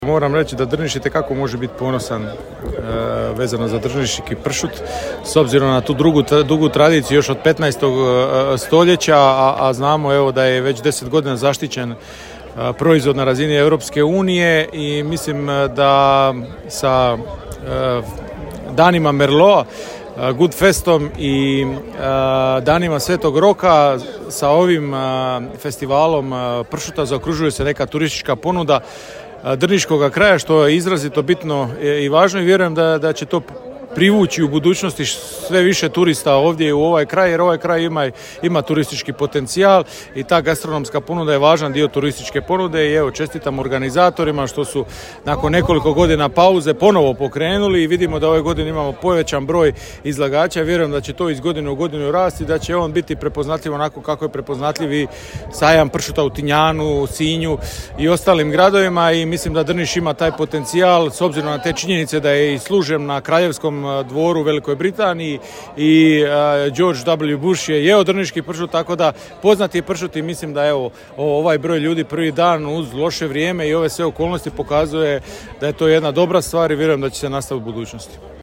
Večeras je u Drnišu svečano otvoren 8. Međunarodni festival pršuta, u organizaciji Turističke zajednice Grada Drniša te Grada Drniša.
Evo izjava:
Saborski zastupnik Ivan Malenica: